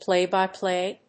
アクセントpláy‐by‐plày